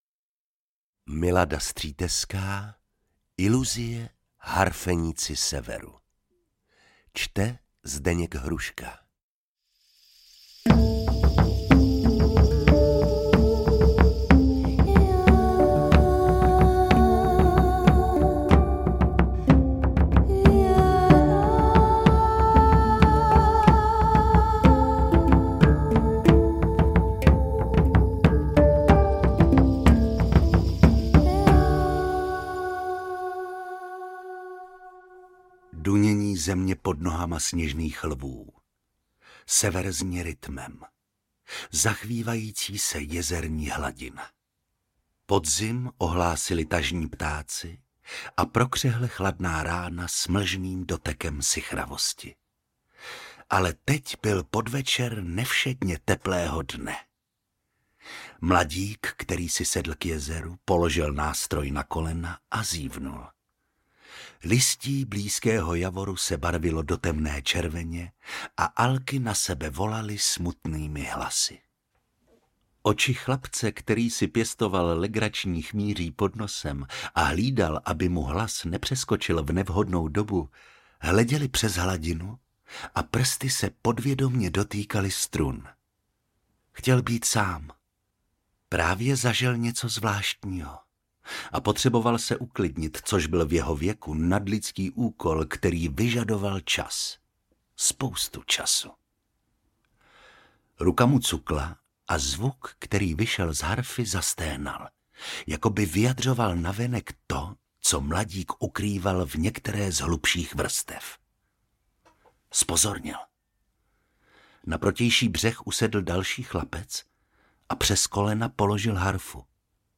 AudioKniha ke stažení, 39 x mp3, délka 27 hod. 10 min., velikost 1490,3 MB, česky